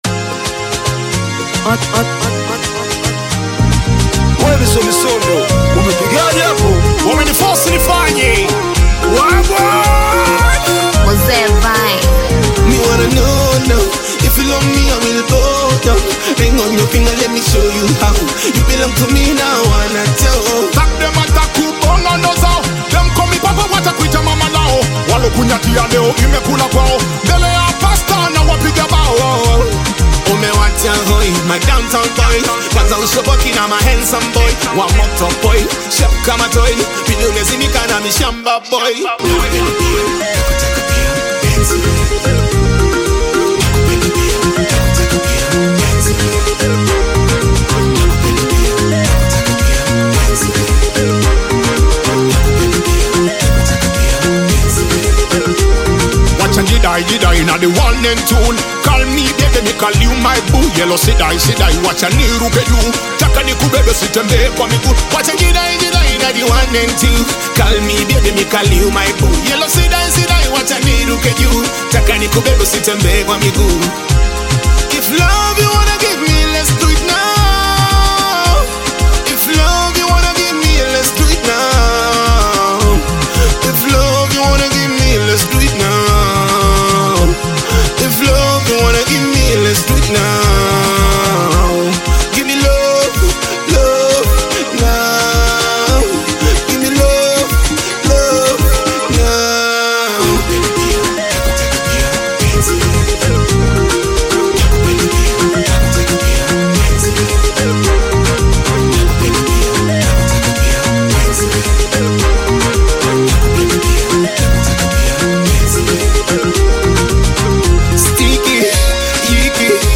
Singeli music track
Tanzanian Bongo Flava